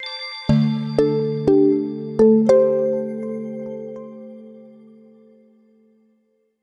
PowerOn.wav